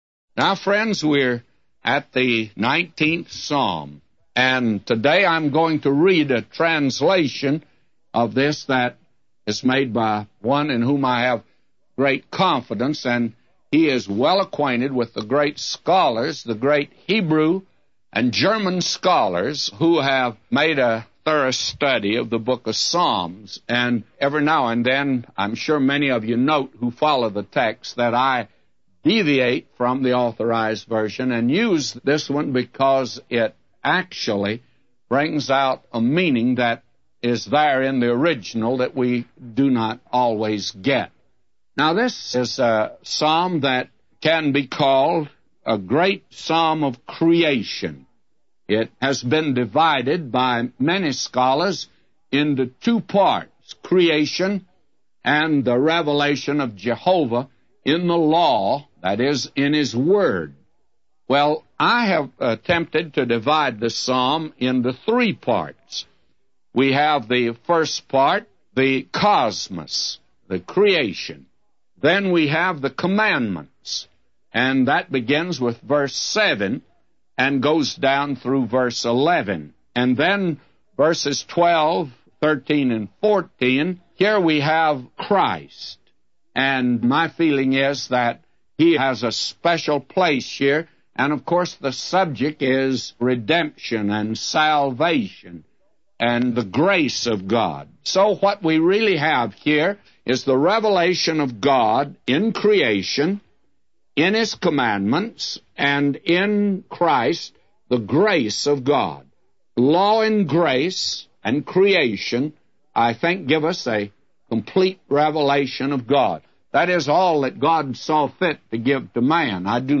A Commentary By J Vernon MCgee For Psalms 19:0-999